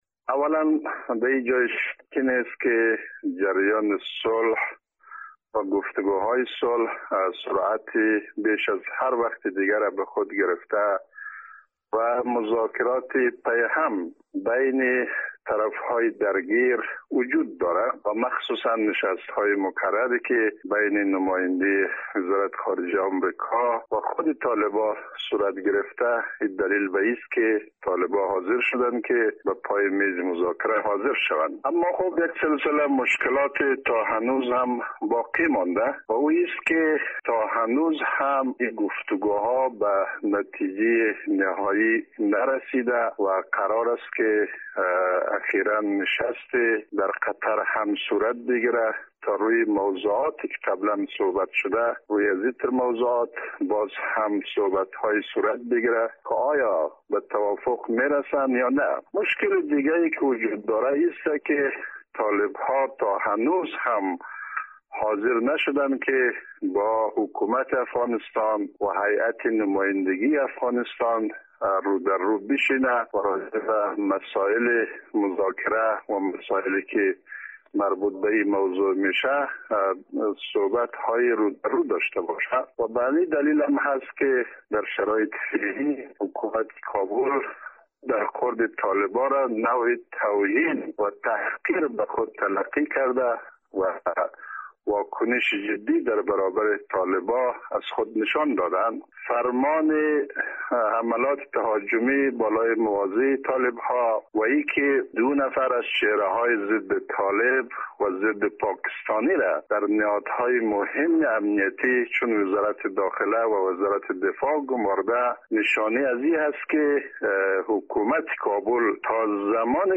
در گفت و گو با خبرنگار رادیو دری